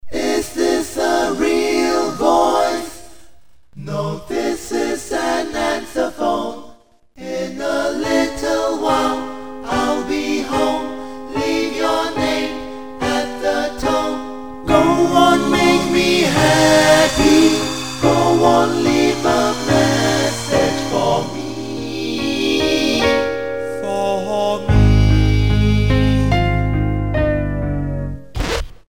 . 8 voices, with 4 parts, plus a piano (and some effects).